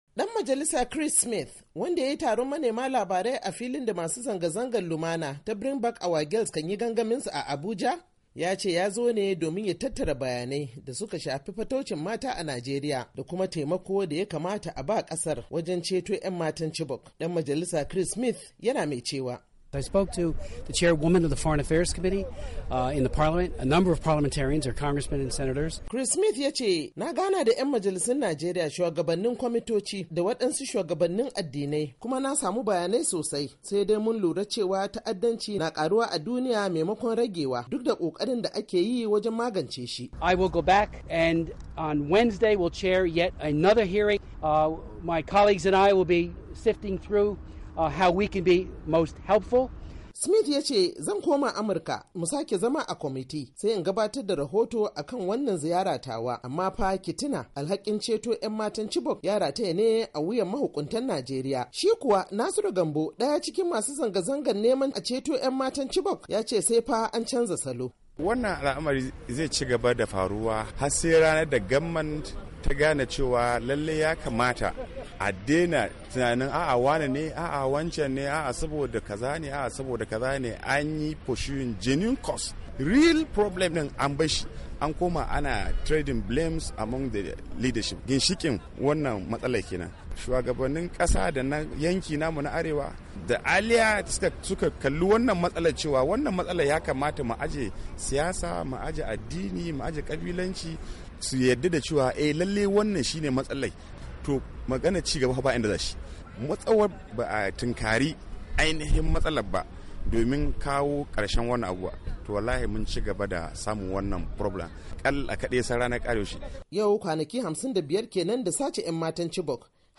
WASHINGTON, DC —  Dan majalisr kasar Amurka mai kula da kwamitin harkokin Afirka da kare hakkin biladam da lafiyar su Congressman Chris Smith, R-N.J. da yake ziyaran Najeriya yayi wa ‘yan jarida jawabi a filin da ake yingaggamin akan ‘yan matan Chibok.